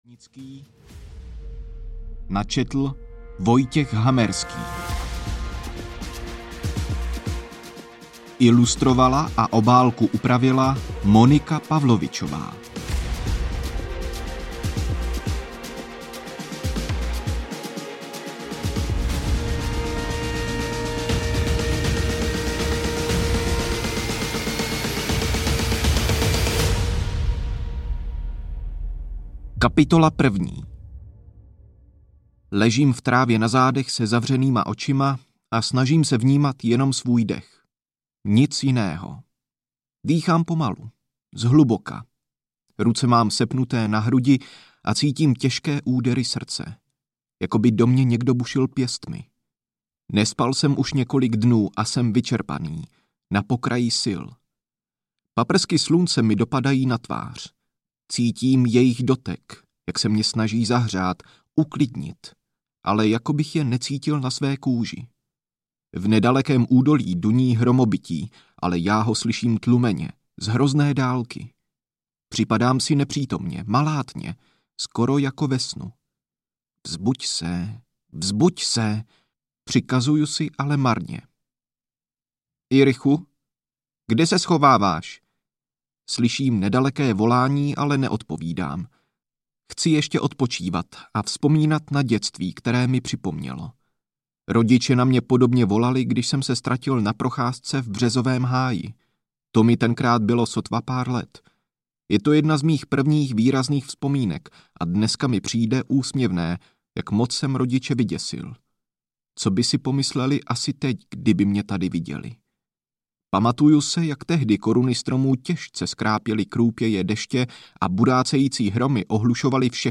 Březový háj audiokniha
Ukázka z knihy